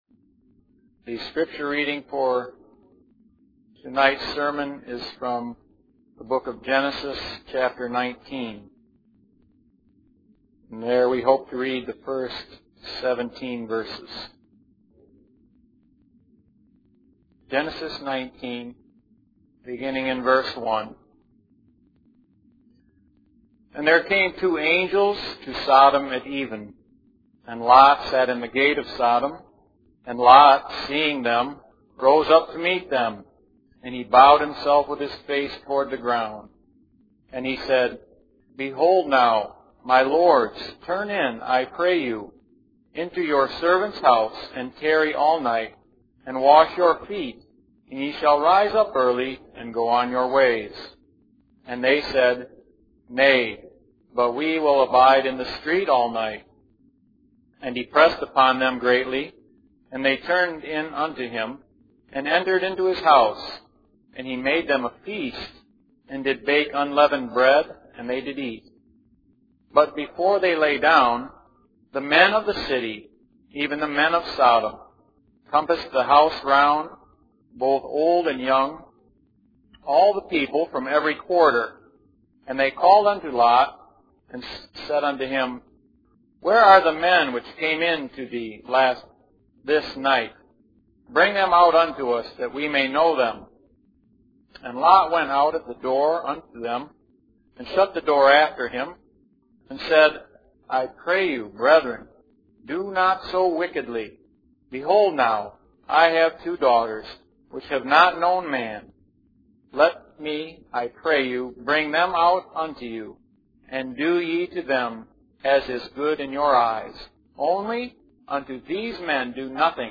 In this sermon, the preacher focuses on the story of Lot and his wife from the book of Luke. The sermon is divided into four points: what Lot was himself, what the text tells us about him, the reasons for his lingering, and the consequences of his actions. The preacher emphasizes the importance of remembering Lot's wife as a warning to all believers.